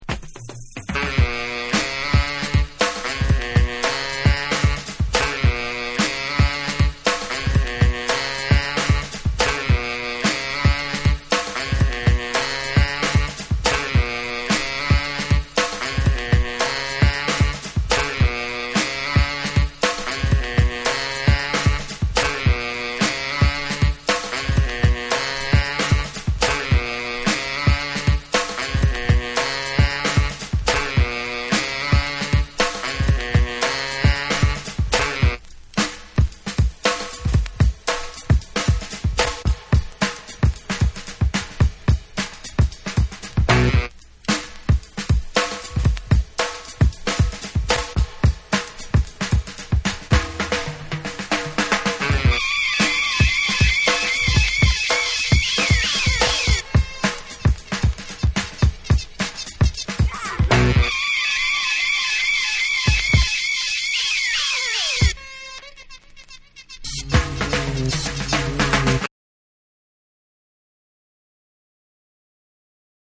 BREAKBEATS / JAPANESE